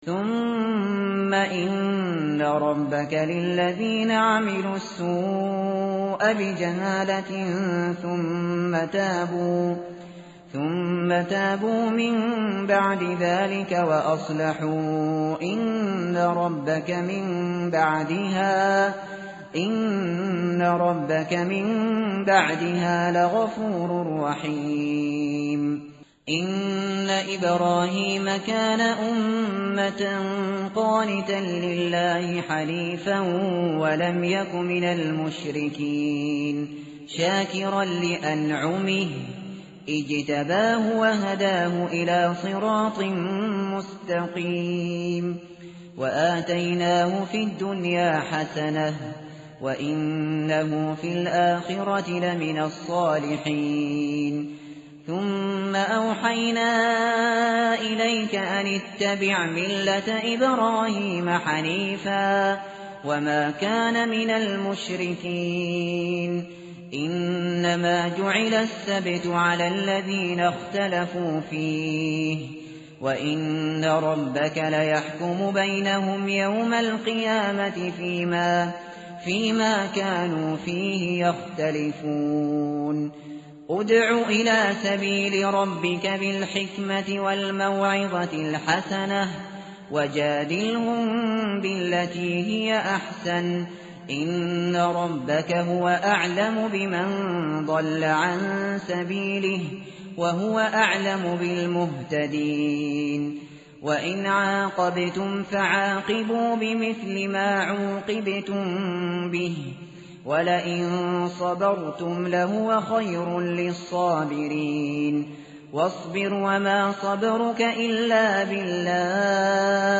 متن قرآن همراه باتلاوت قرآن و ترجمه